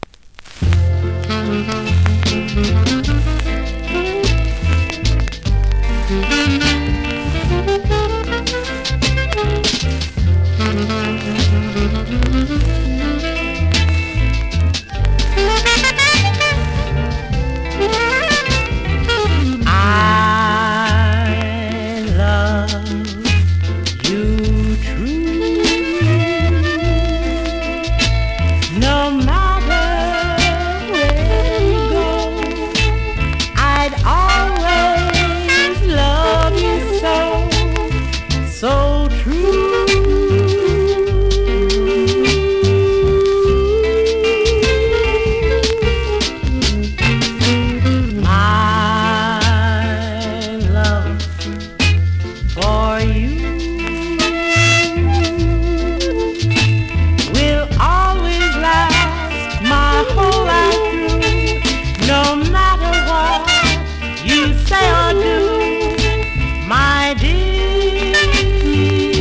NEW IN!SKA〜REGGAE
スリキズ、ノイズそこそこありますが